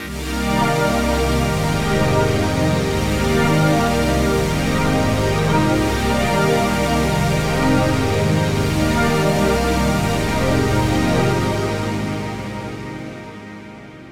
For example, an instance of JUNO-60 Chorus will soften up the D-50 Soundtrack layer, while the JC-120 Jazz Chorus guitar amplifier emulation can add grit and motion to the JD-800 Massive Pad.
This layer needs texture and motion, so RE-201 Space Echo with plenty of dubby tape echoes followed by the Auto Pan effect pulled from ZENOLOGY FX works nicely.
On the busses, try adding reverb and delay, and then sending a little of each layer to both effects.
Galaxias-effects.wav